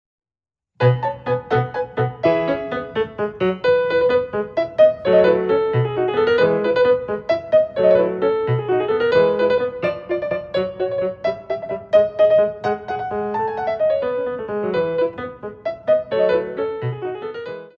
Ballet class music for first years of ballet
8x8 - 6/8